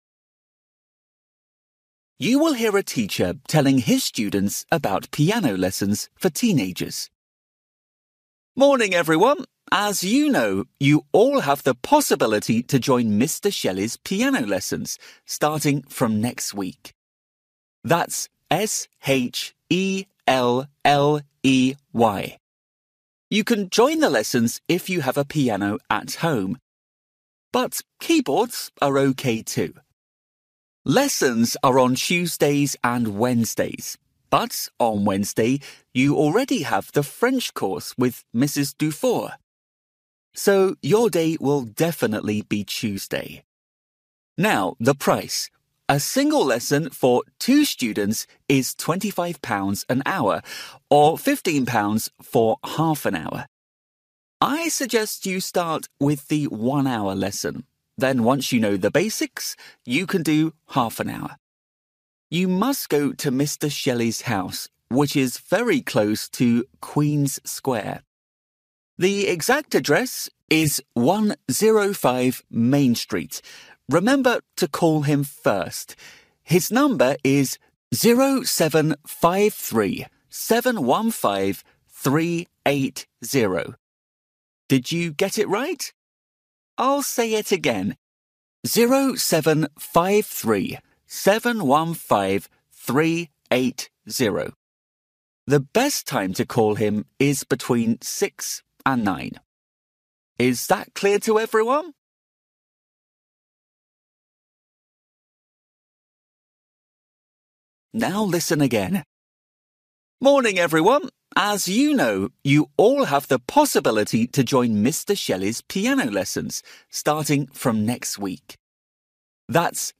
You will hear a teacher telling his students about piano lessons for teenagers.